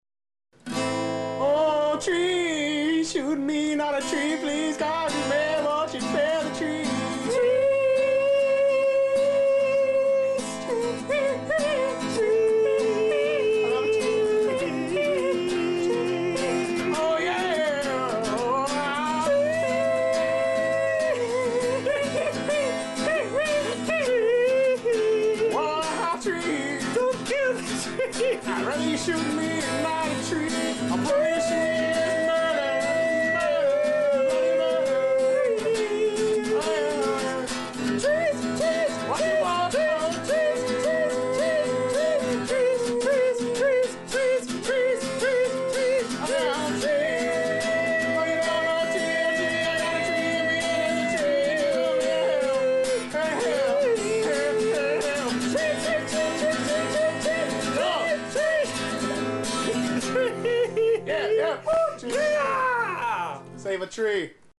jam session